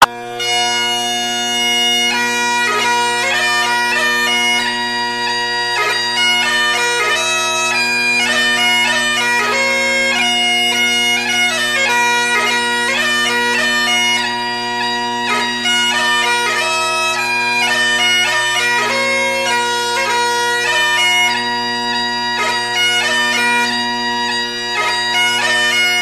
bagpipes-scotland_14199.mp3